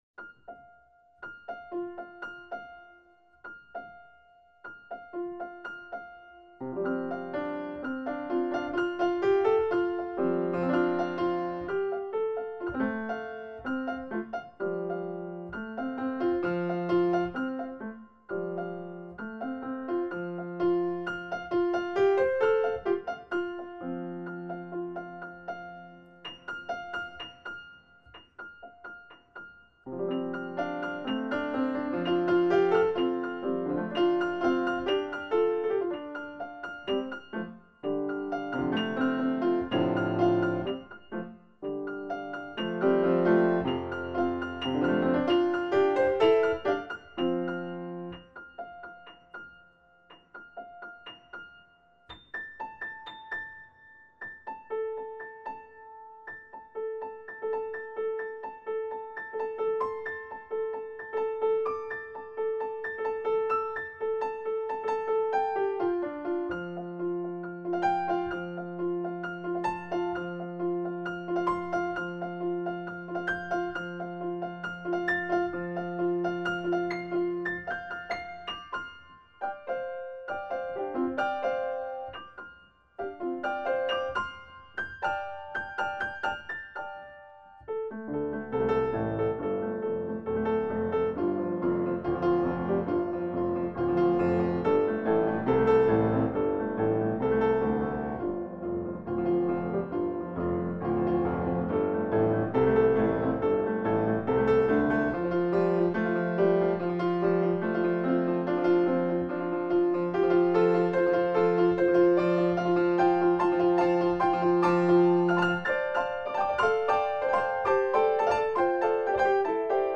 尝试以钢琴来诠释古琴曲